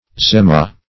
uzema - definition of uzema - synonyms, pronunciation, spelling from Free Dictionary Search Result for " uzema" : The Collaborative International Dictionary of English v.0.48: Uzema \U"ze*ma\, n. A Burman measure of twelve miles.